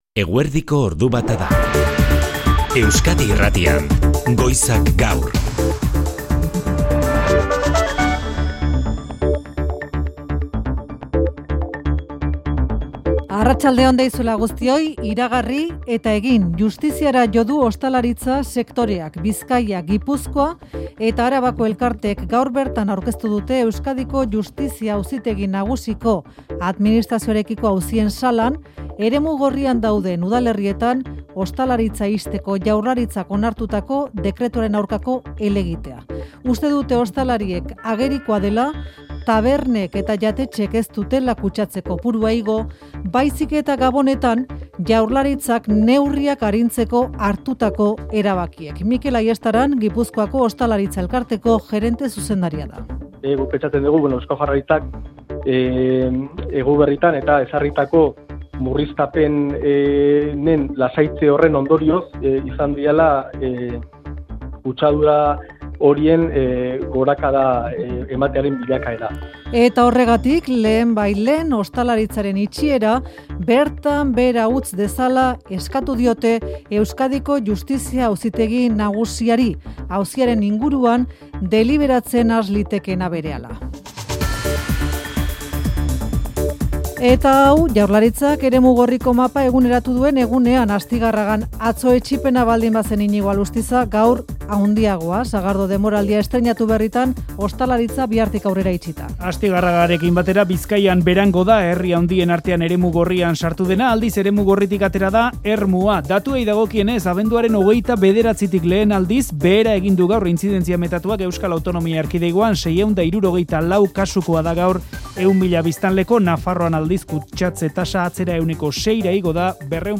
Euskadi Irratiko eguerdiko albistegi nagusia da